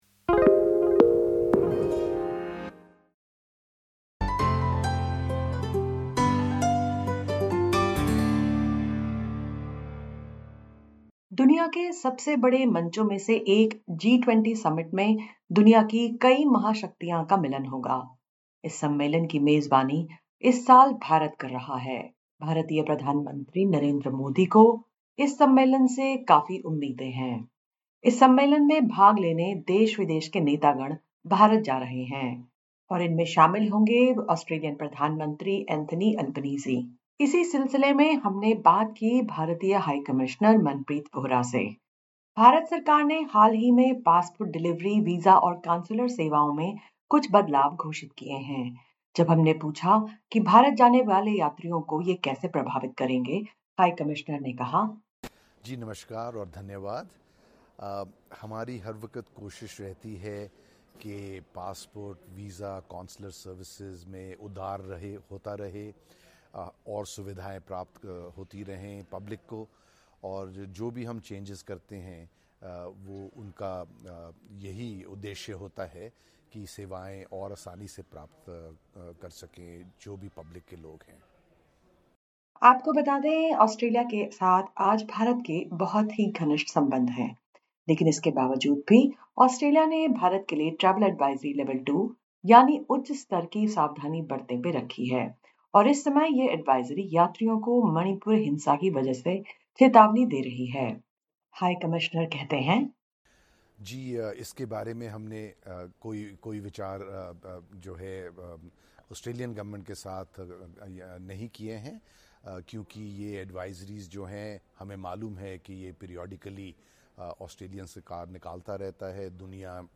Before the high-profile meeting, SBS Hindi conducted an exclusive interview with the Indian High Commissioner to Australia, Manpreet Vohra.